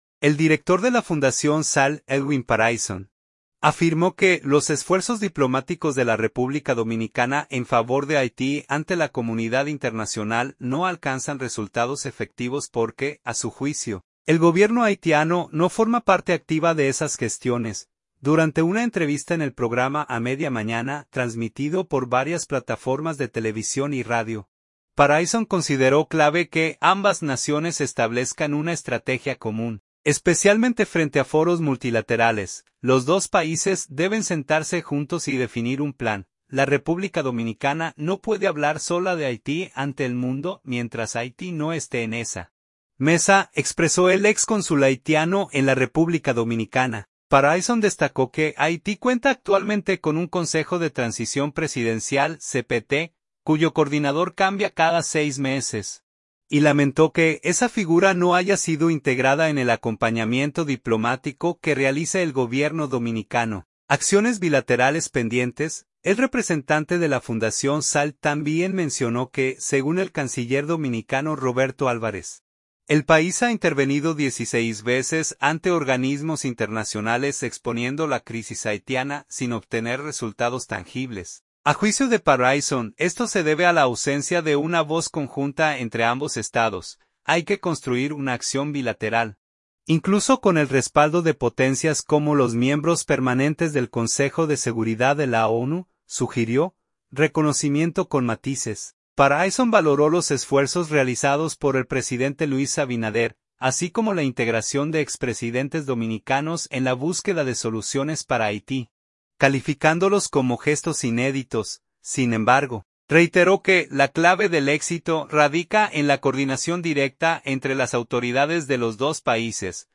Durante una entrevista en el programa A Media Mañana, transmitido por varias plataformas de televisión y radio